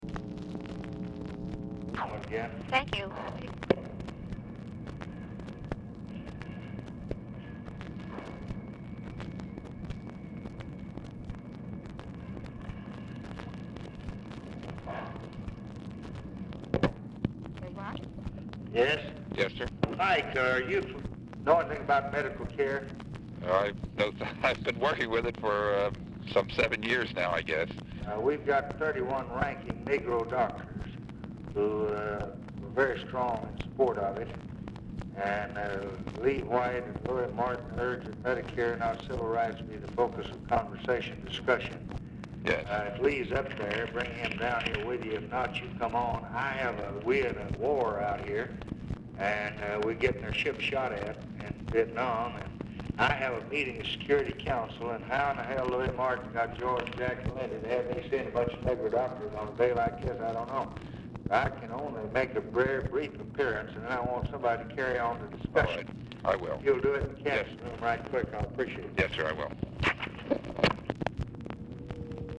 Telephone conversation # 4679, sound recording, LBJ and MYER FELDMAN, 8/4/1964, time unknown | Discover LBJ